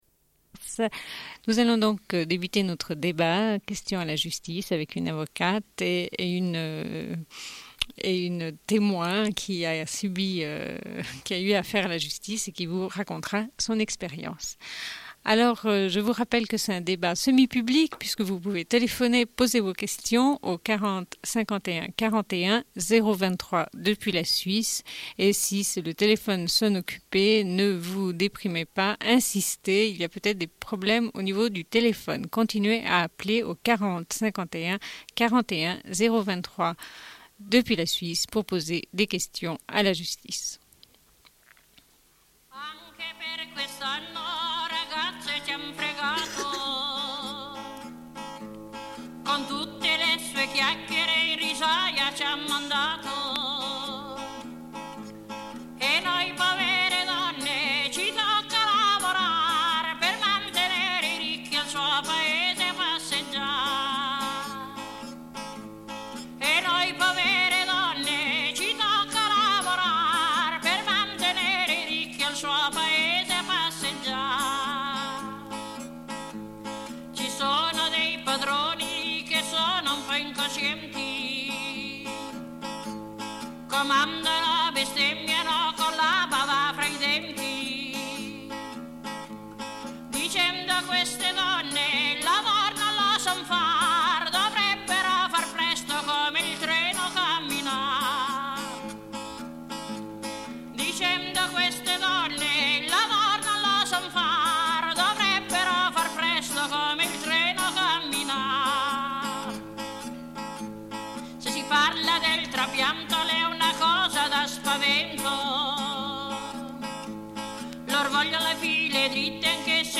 Une cassette audio, face A47:24